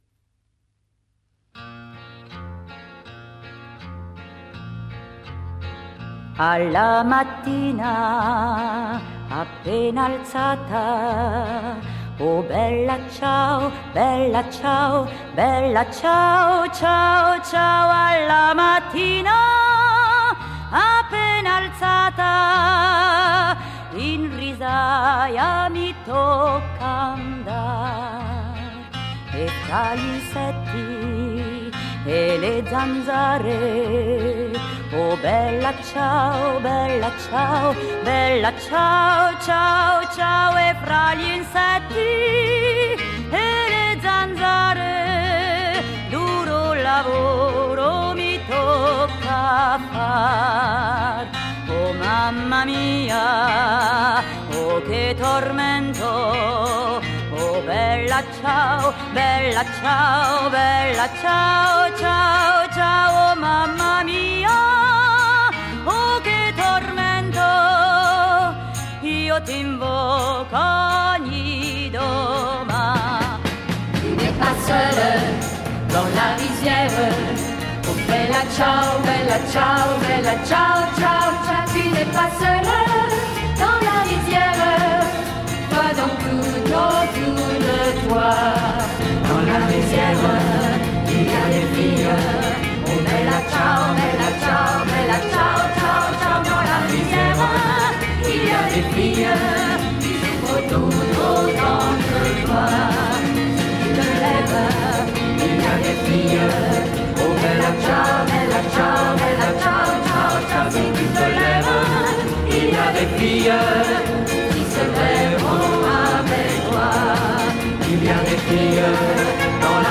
Enregistré au Théâtre Gérard Philipe de St-Denis